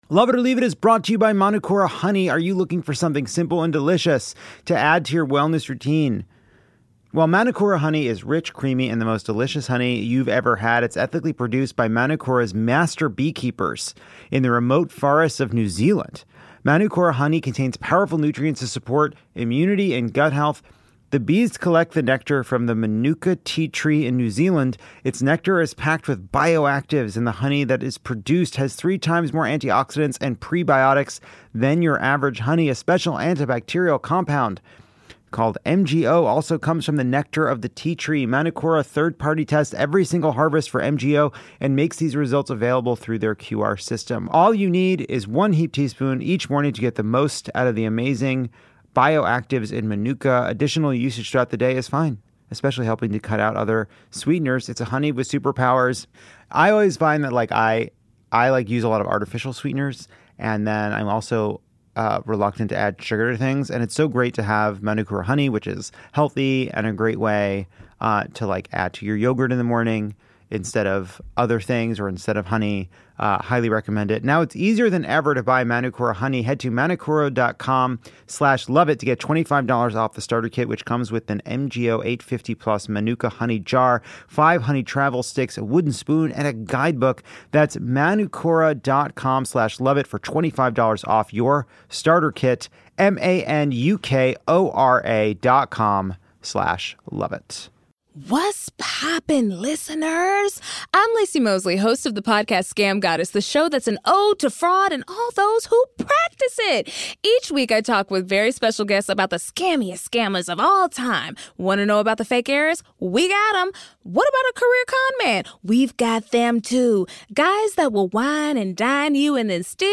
Comedian Guy Branum and the Scam Goddess herself Laci Mosley stop by to distract us with sweet, sweet television and rate the biggest scams (so far) of the second Trump administration. Plus Oscar nominations, sexually charged honey, and the comfort foods getting us through it all.